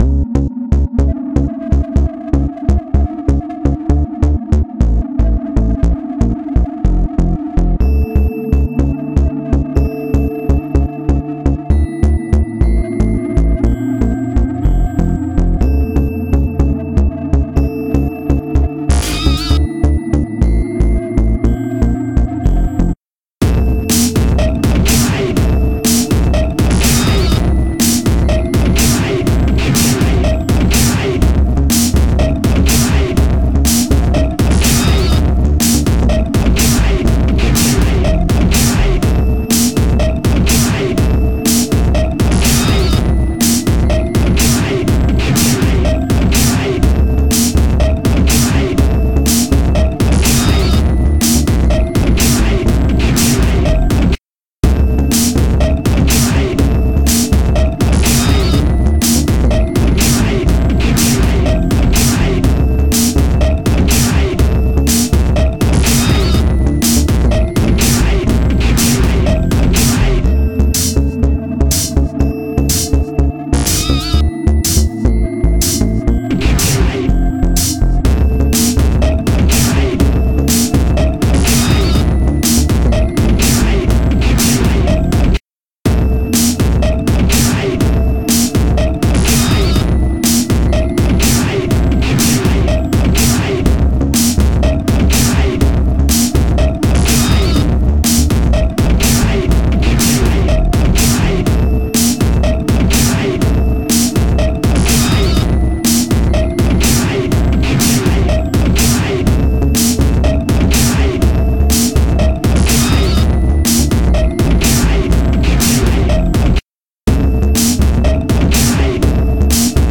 crazy
Sci-Fi
Instrumental